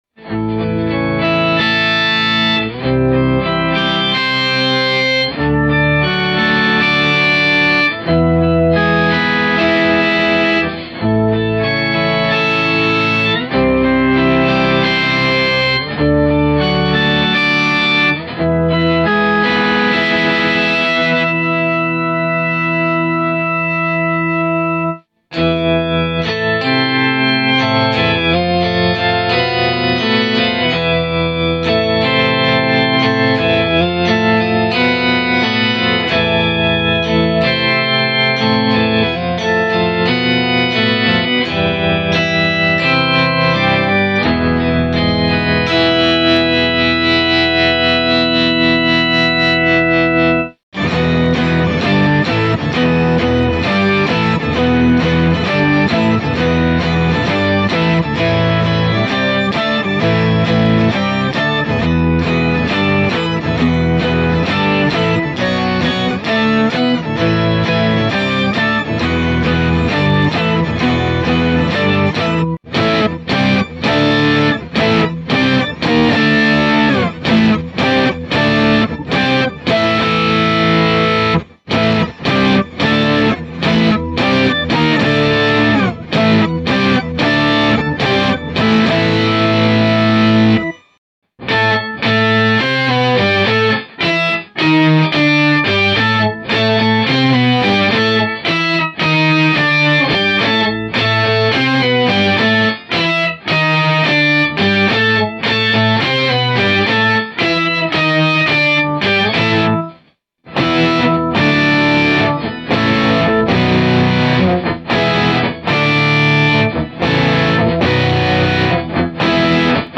I was messing around with the New EHX B9 Organ pedal.
I had it set for a pretty dirty rock organ sound and then some of the Cathedral type sounds also.